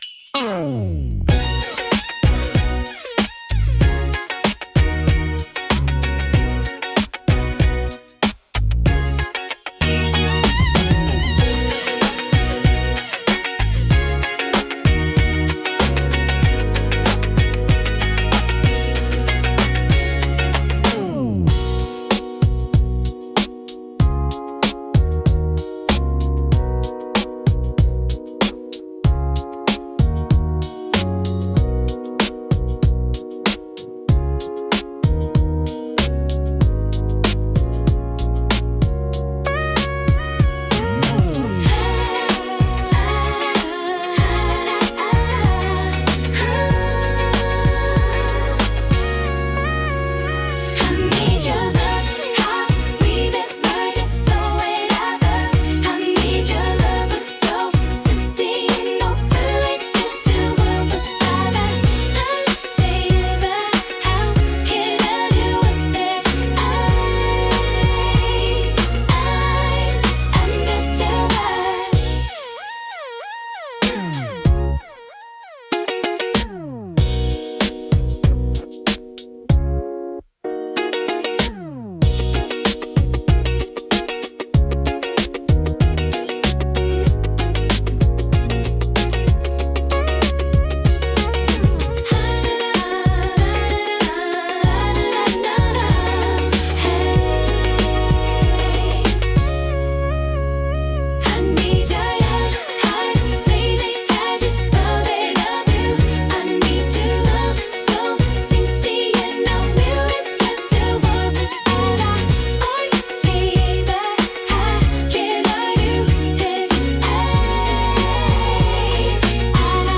聴く人に心地よいリズム感と温かみを届ける、優雅で感性的なジャンルです。